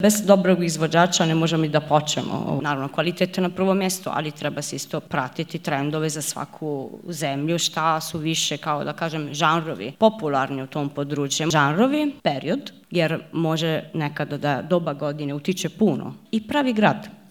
Jedan od panela današnjeg susreta bila je i diskusija s temom "Napuniti Arenu" na kojoj su stručnjaci razgovarali o razlozima zbog kojih izvođači pune poznatu zagrebačku koncertnu dvoranu.